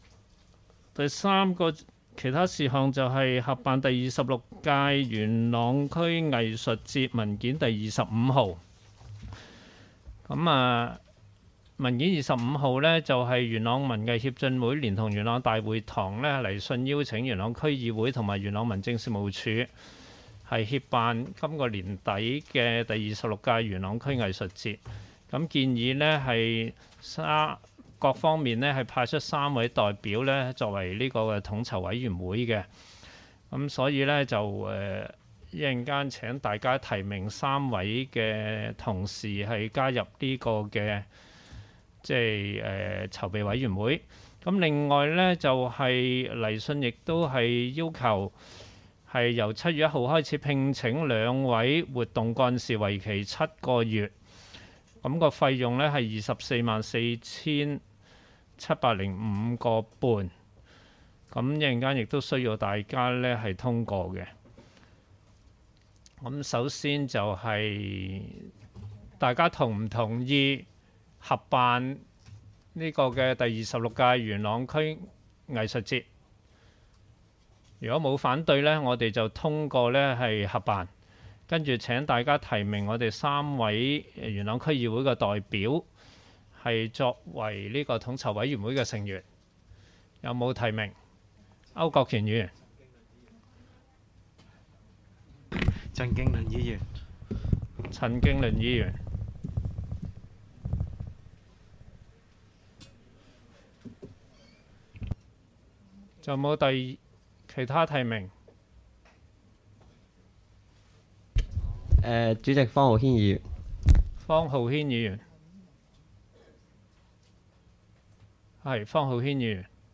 区议会大会的录音记录
地点: 元朗桥乐坊2号元朗政府合署十三楼会议厅